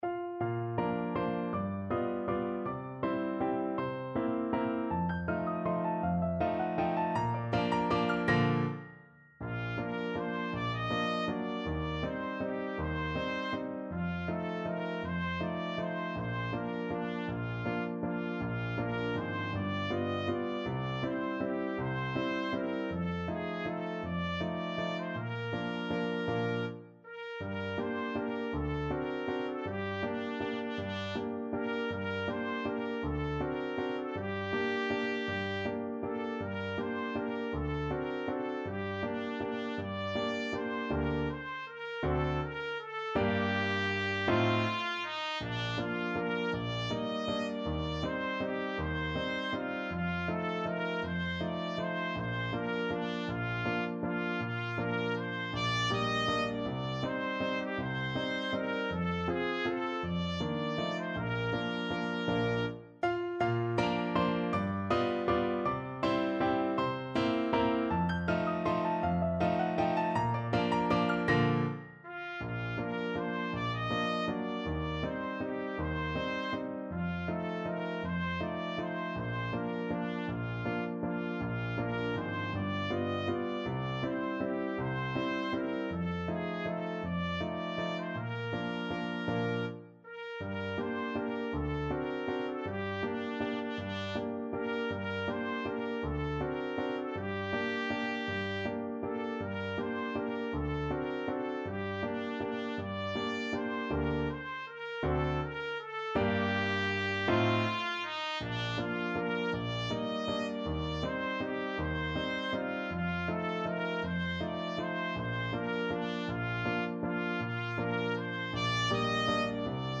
3/4 (View more 3/4 Music)
~ = 160 Tempo di Valse
Traditional (View more Traditional Trumpet Music)